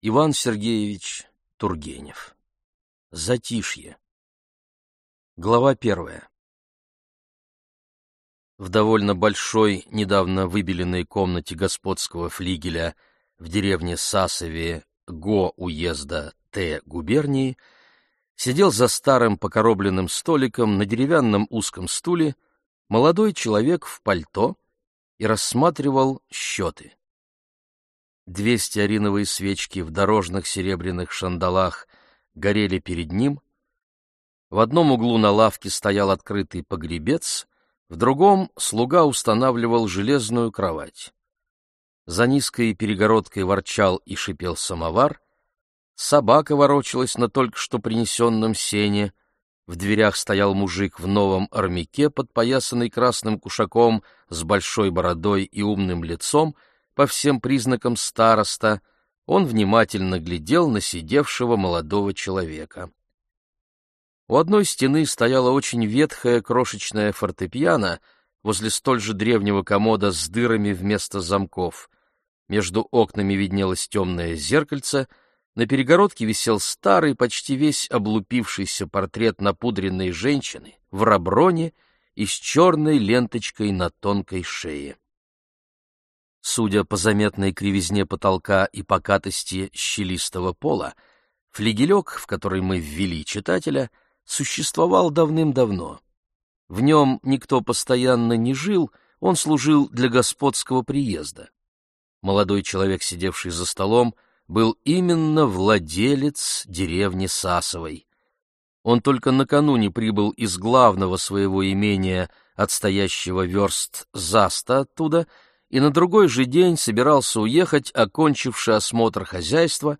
Аудиокнига Затишье | Библиотека аудиокниг